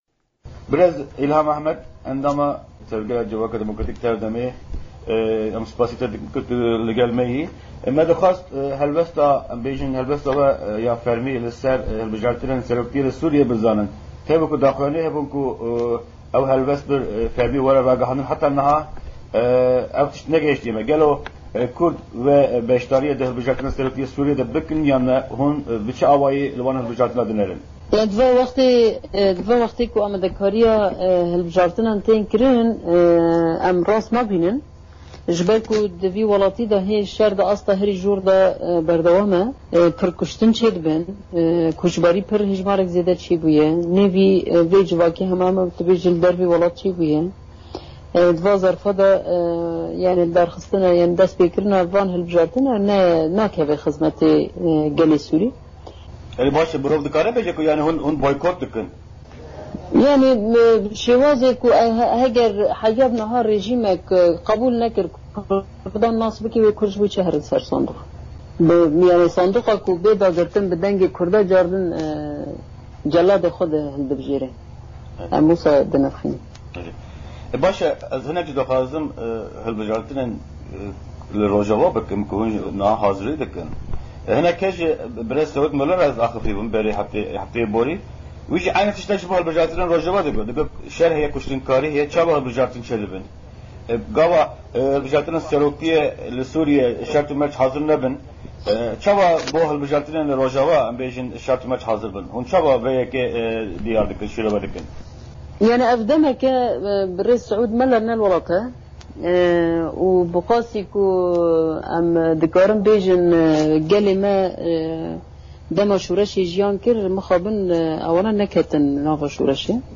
Endama Rêvebirîya Tevgera Civaka Demokratîk (TEV-DEM) Îlham Ahmed, di hevpeyvîna Dengê Amerîka de helwesta Kurdan ya ser hilbijaritinên seroktîyê li Sûrîyê dinirxîne.